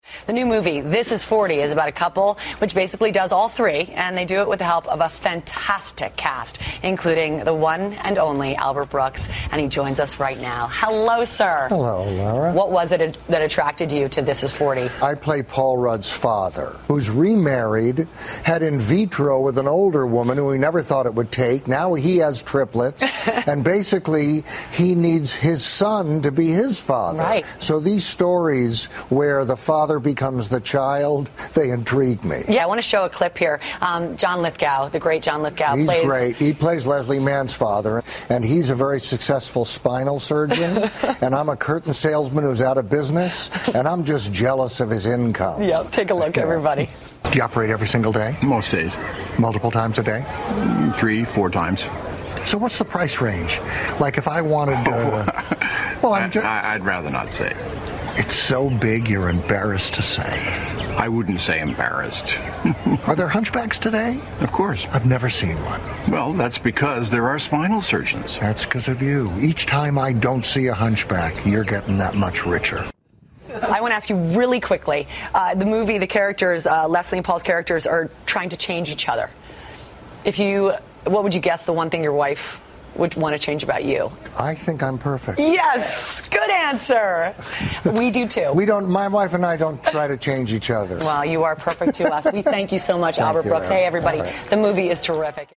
访谈录 2013-01-02＆01-04“四十不惑”阿尔伯特·布鲁克斯专访 听力文件下载—在线英语听力室